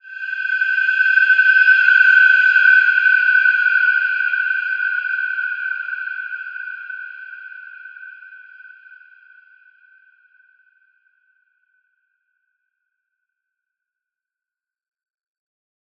Wide-Dimension-G5-f.wav